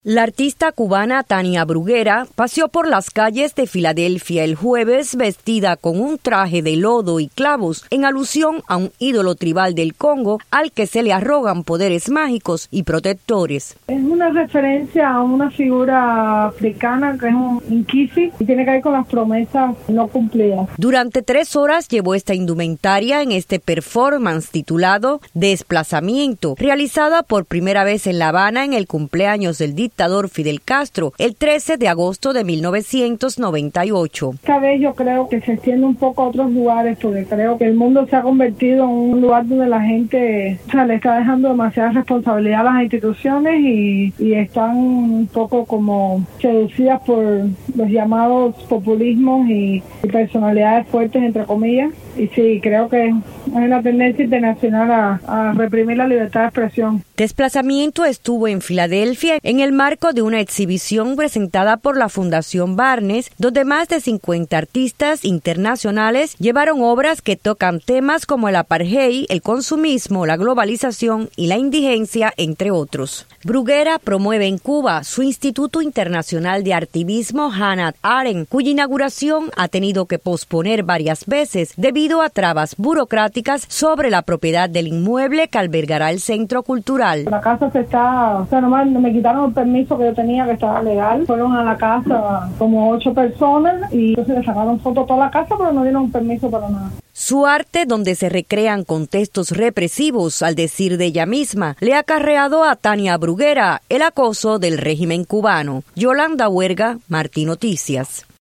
conversó con la artista